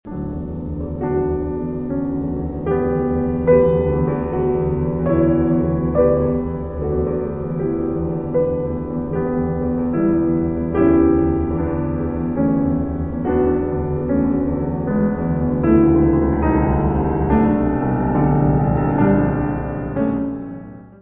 piano.